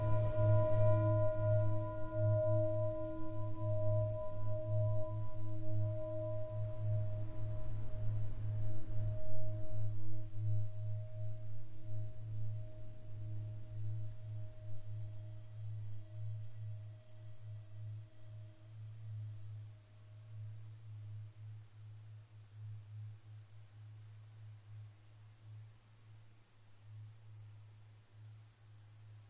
歌唱碗的侧面和边缘振动产生声音，其特点是有一个基本频率（第一次谐波）和通常两个可听的谐波泛音（第二次和第三次谐波）。
标签： 双行星的频率 听起来换冥想和放松 土星 天王星 Tibetanische-Klangschalen-声音 声音-的藏族歌唱碗
声道立体声